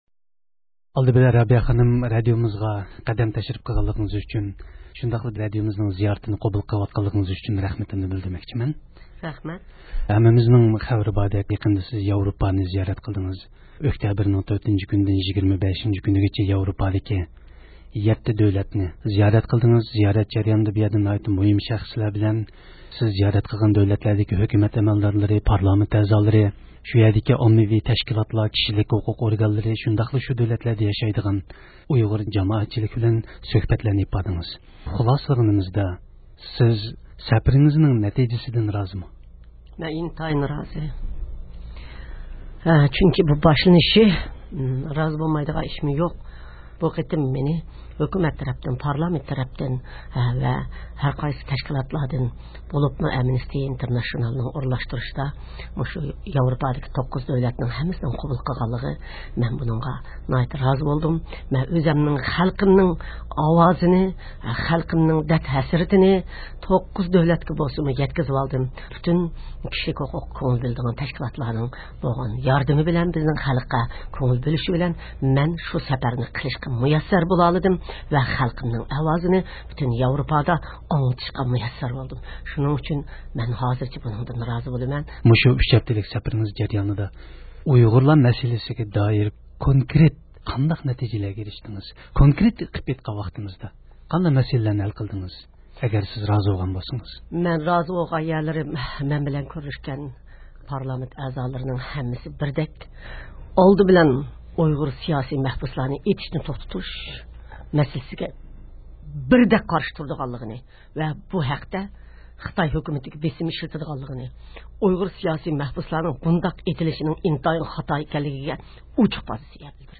ياۋروپا سەپىرىدىن كېيىن رابىيە قادىر خانىمنى زىيارەت – ئۇيغۇر مىللى ھەركىتى